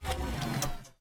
popup_cangmen_down.ogg